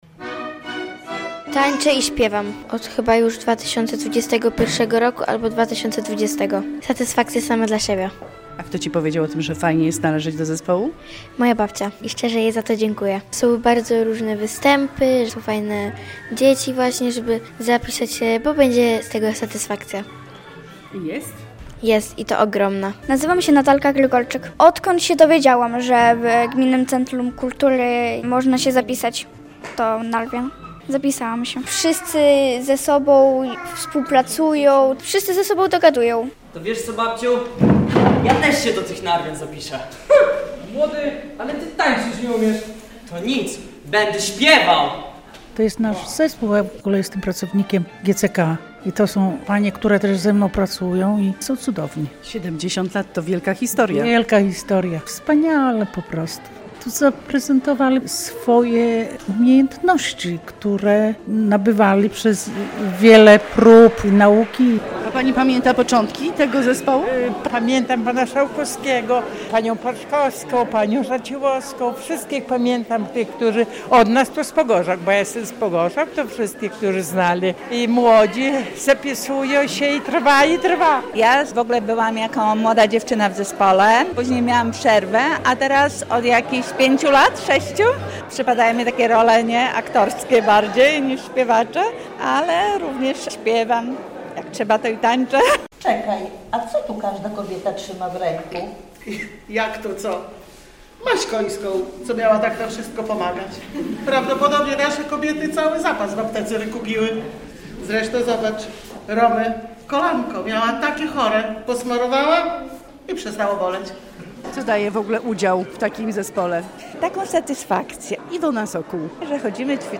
relacja
31 stycznia o 18:00 w Operze Podlaskiej odbędzie się uroczysty koncert jubileuszowy, ale prapremiera programu jubileuszowego przed tym wielkim wydarzeniem odbyła się w Wiejskim Domu Kultury w Pogorzałkach.